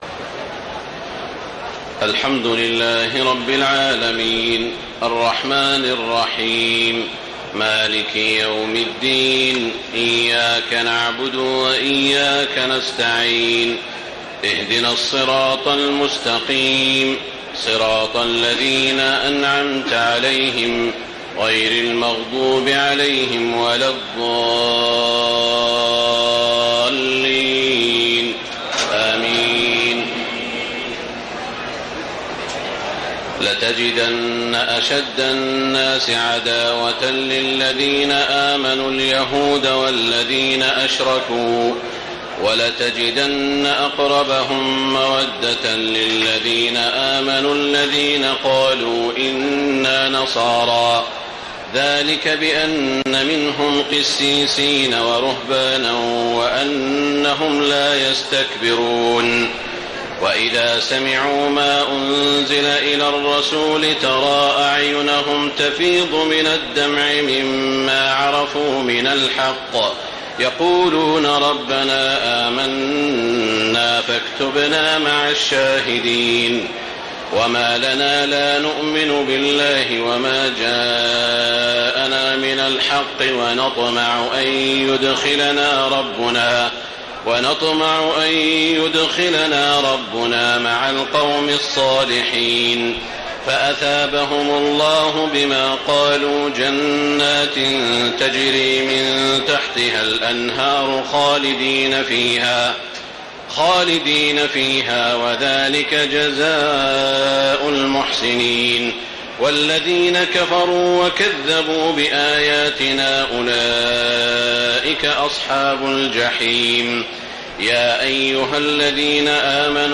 تهجد ليلة 27 رمضان 1434هـ من سورتي المائدة (82-120) و الأنعام (1-58) Tahajjud 27 st night Ramadan 1434H from Surah AlMa'idah and Al-An’aam > تراويح الحرم المكي عام 1434 🕋 > التراويح - تلاوات الحرمين